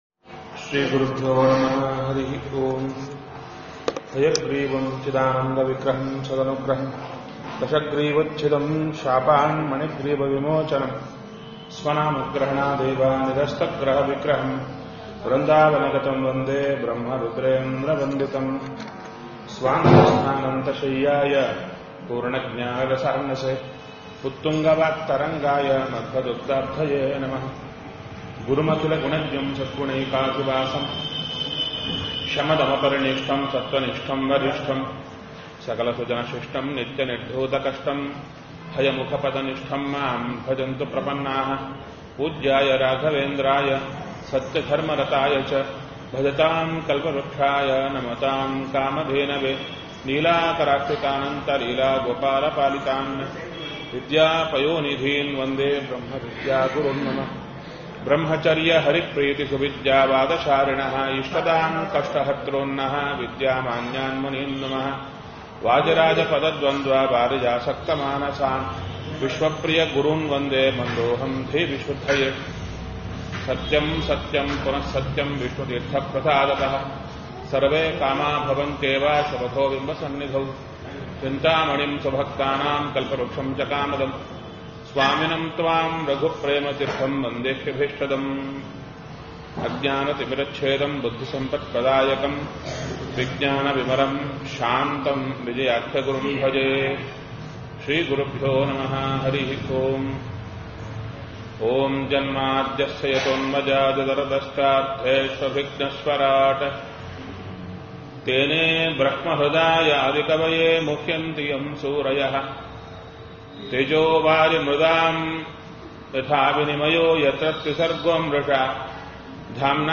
Spiritual discourses, audio messages, events, and downloadable resources from Kurnool Achars Chintana.